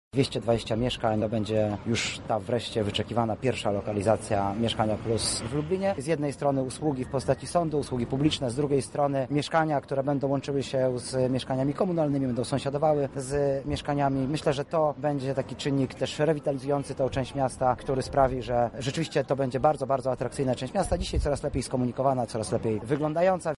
O tym, jak inwestycja będzie wyglądała w naszym mieście, mówi Wiceminister Inwestycji i Rozwoju Artur Soboń: